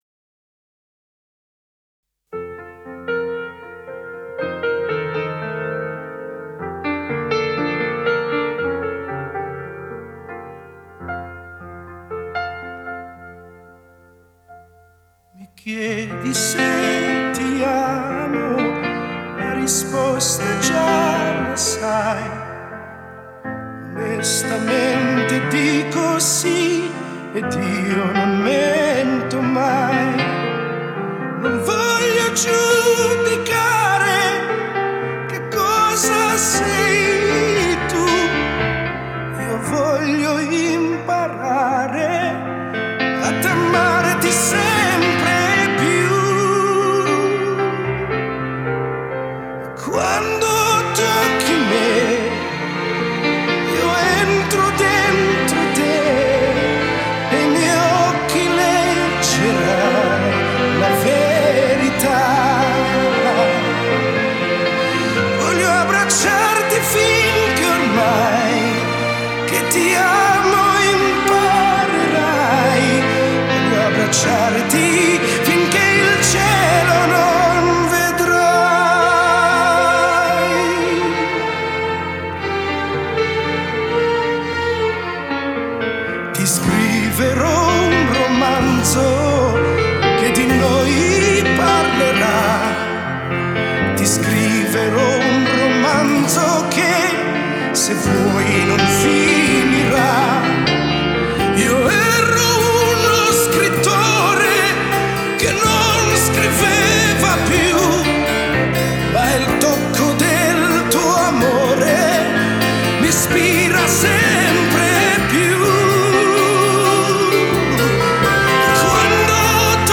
(там и звук получше)